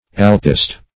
Search Result for " alpist" : The Collaborative International Dictionary of English v.0.48: Alpist \Al"pist\, Alpia \Al"pi*a\, n. [F.: cf. Sp.